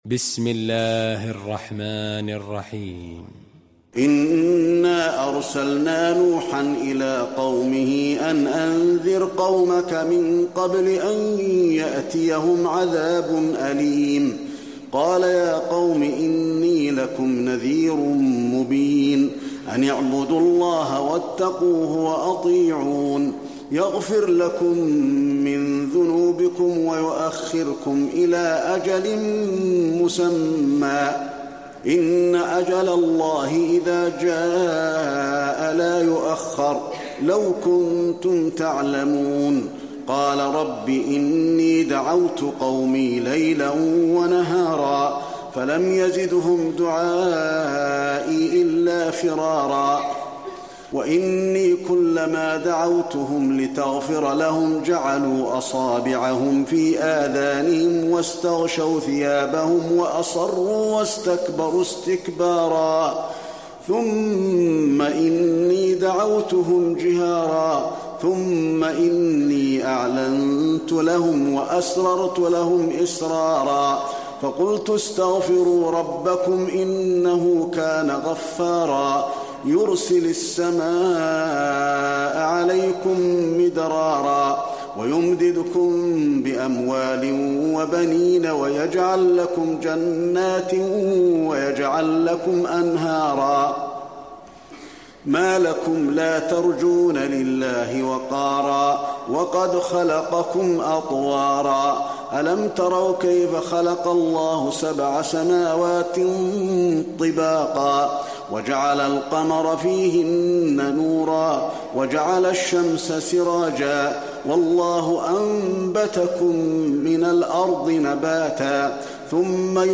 المكان: المسجد النبوي نوح